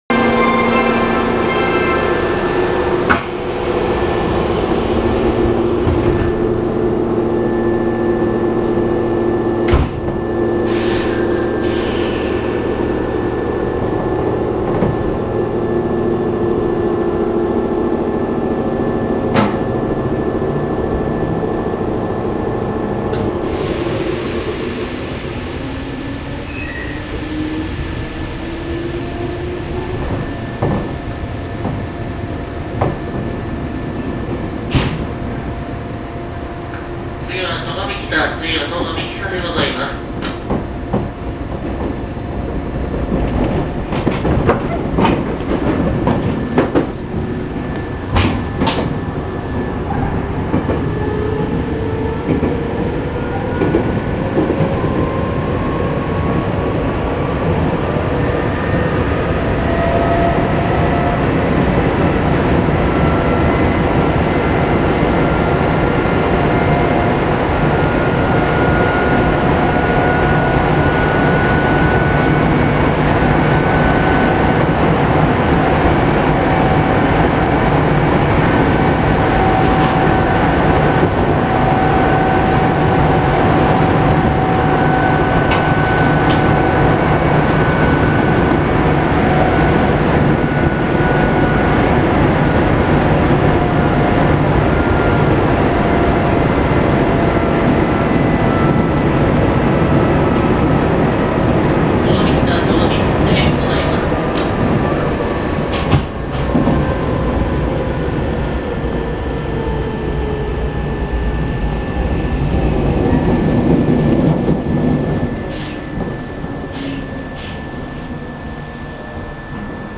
〜車両の音〜
・3000系走行音
【泉北高速線】光明池〜栂・美木多（2分16秒：744KB）…3551Fにて。
ごく普通の抵抗制御。